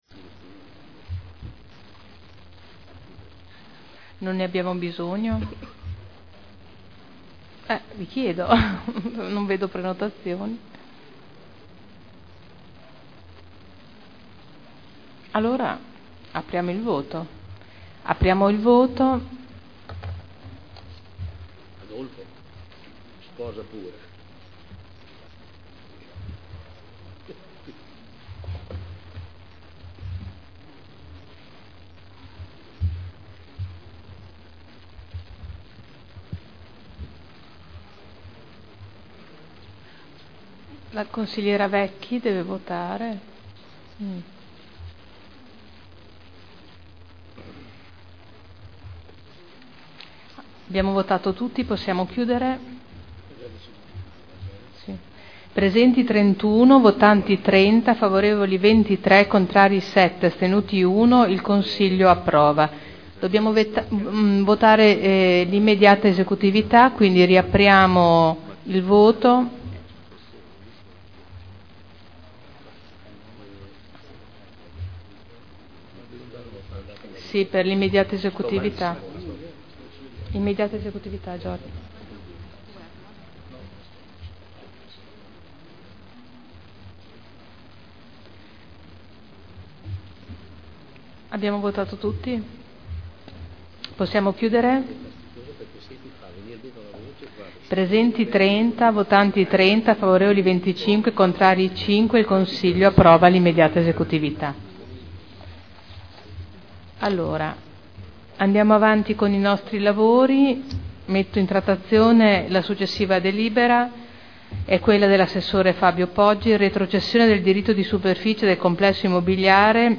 Seduta del 23/12/2010.